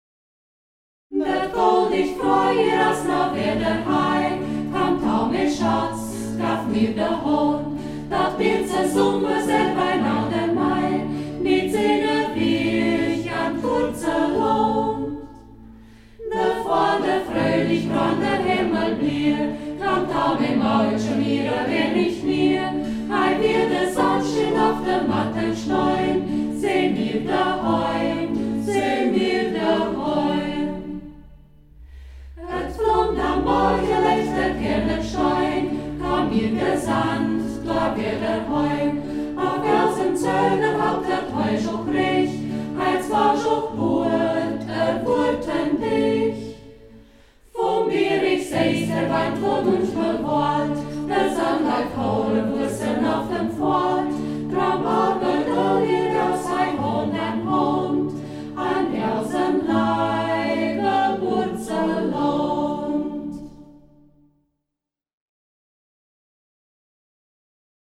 Ortsmundart: Zeiden